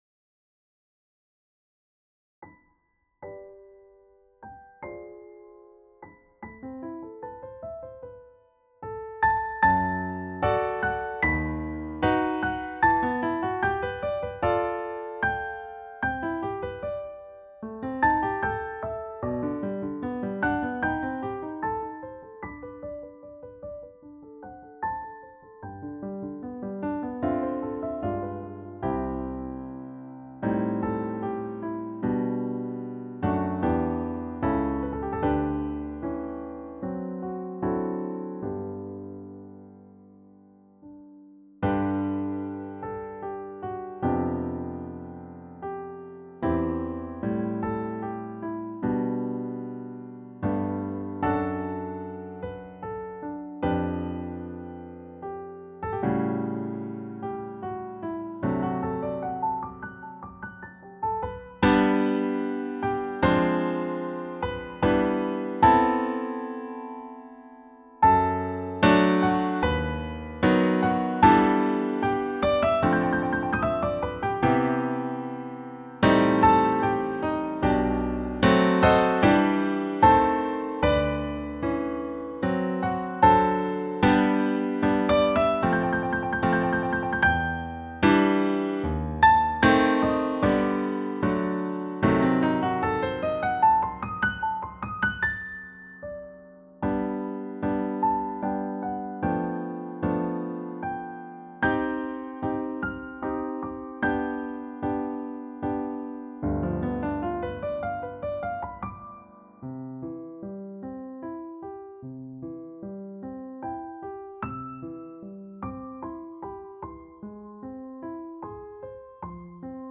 classic Christian hymn
arranged here as a beautiful piano solo.
Solo Piano